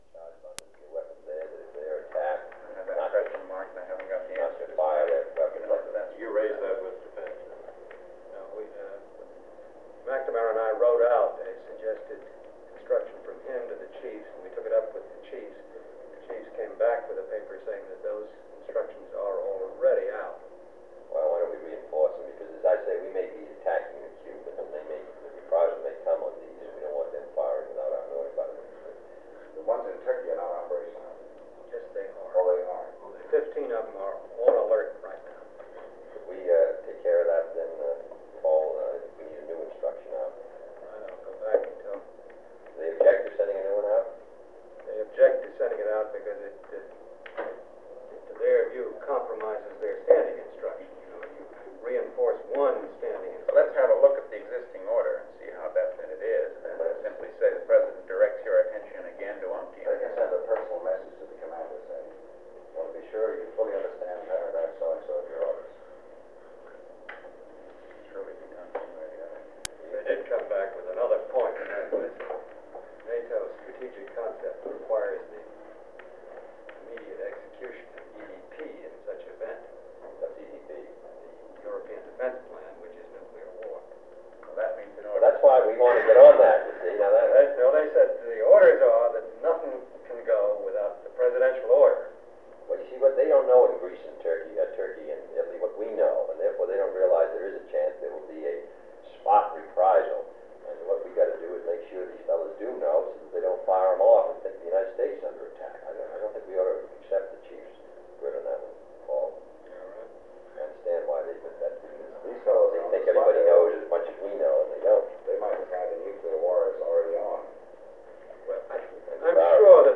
Secret White House Tapes | John F. Kennedy Presidency Meeting of Berlin Group on Crisis Planning Rewind 10 seconds Play/Pause Fast-forward 10 seconds 0:00 Download audio Previous Meetings: Tape 121/A57.